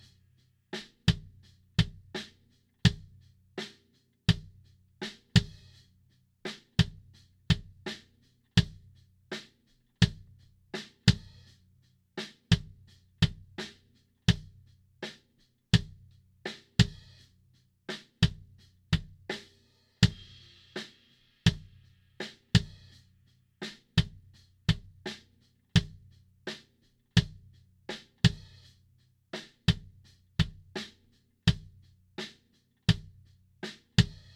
Парни, задачка такая: Лайв сессия барабанов.
Баунс то миди работает не очень точно, много дублированных нот, чистить забадаижся) В примерах, как и полагается просачиваются другие микрофоны, нужно в конечном миди получить чистый и точный миди бочки и снейра.